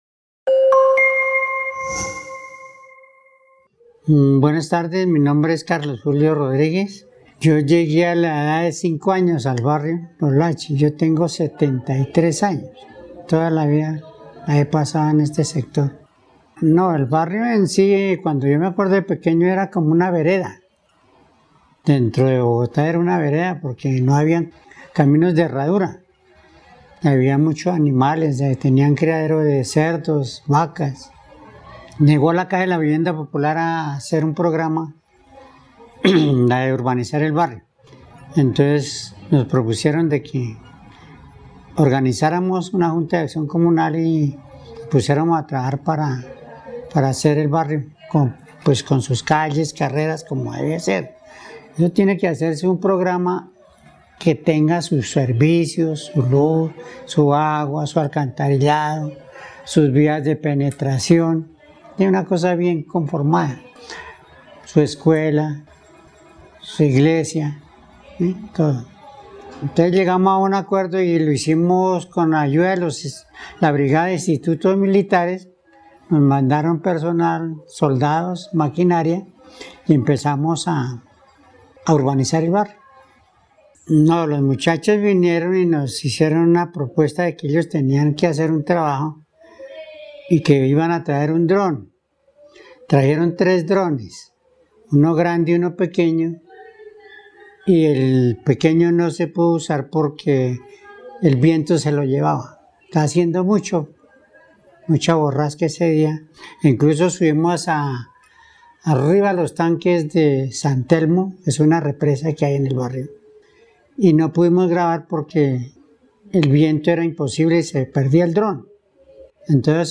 Describe la evolución del barrio desde que era una vereda hasta la actualidad. El testimonio fue grabado en el marco de los Clubes de Memoria y su participación en el programa Patrimonio y Memoria, de la línea de Espacios Creativos de BibloRed.